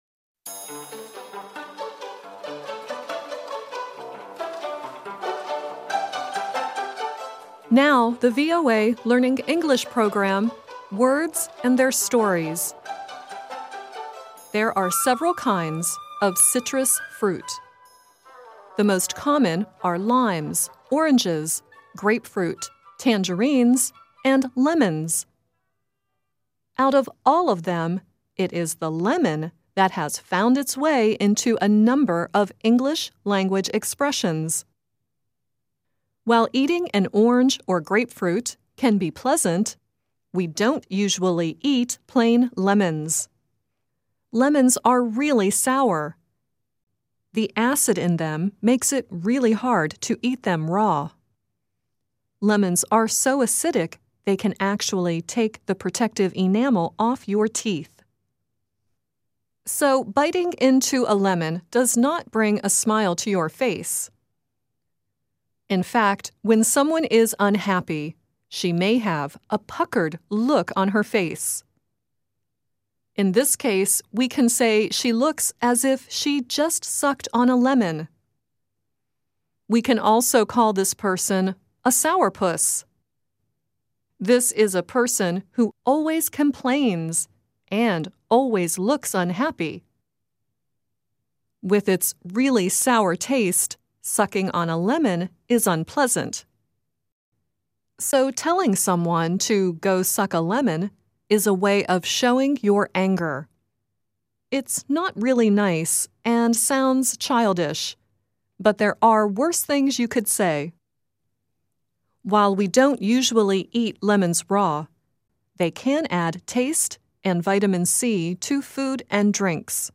Peter, Paul and Mary sing the song "Lemon Tree" at the end of the piece.